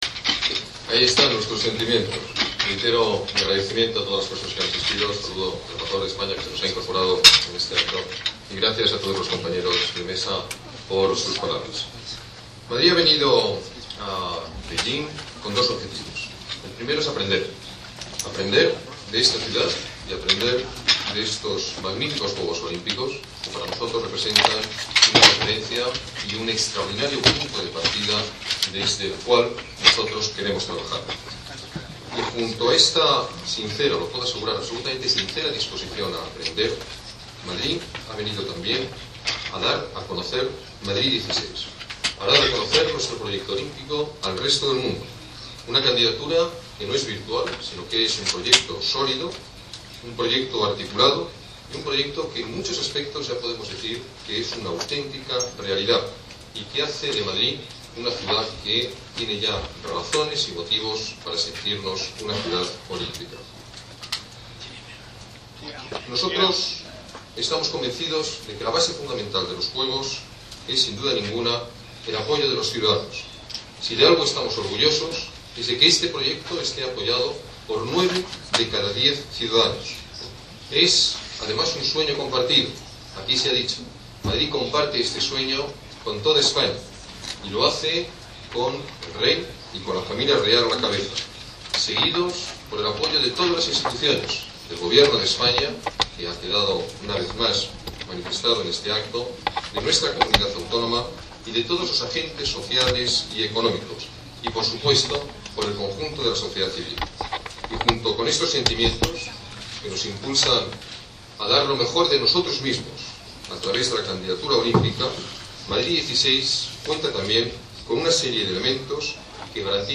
Nueva ventana:Declaraciones del alcalde de Madrid en la rueda de prensa que ha ofrecido hoy en Pekín